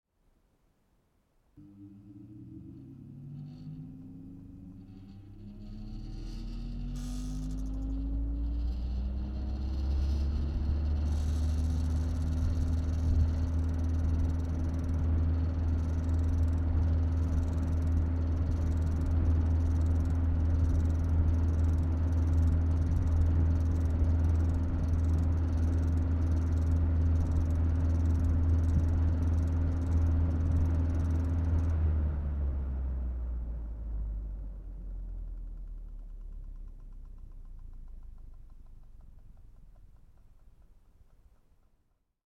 Siemens TF 251 fan